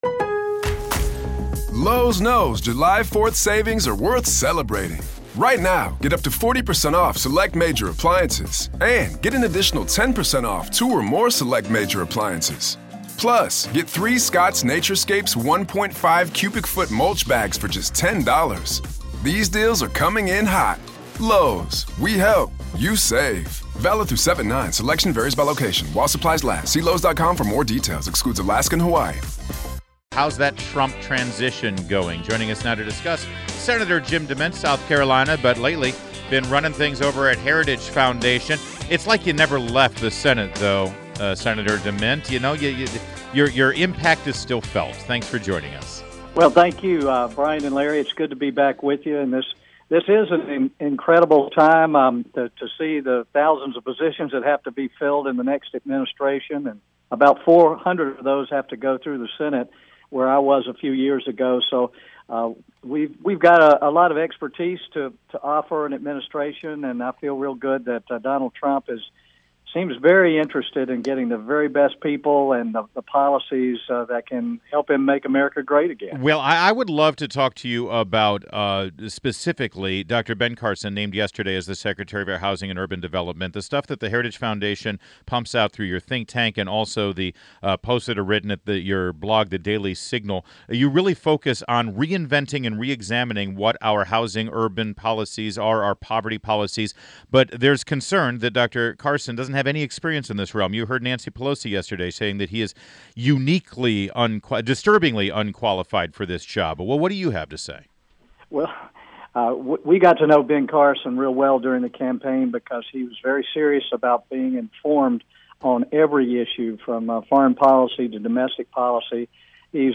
WMAL Interview - SEN. JIM DEMINT - 12.06.16